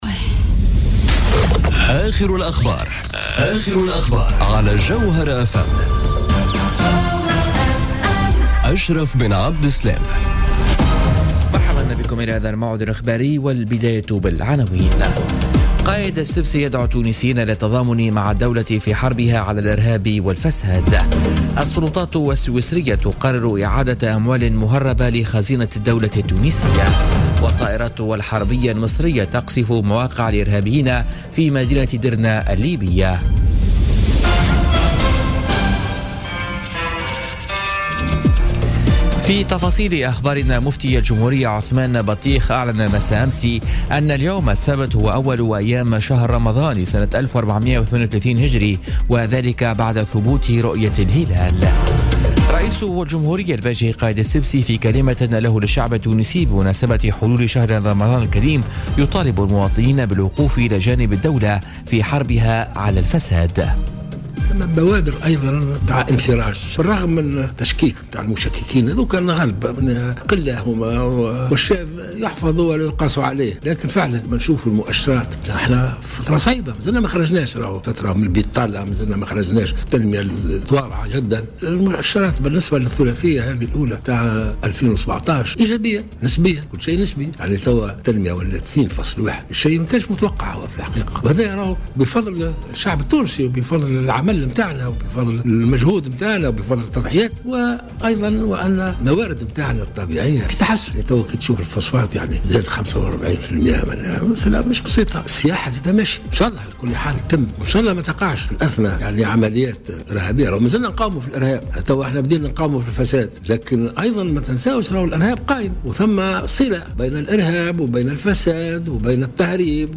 نشرة أخبار منتصف الليل ليوم السبت 27 ماي 2017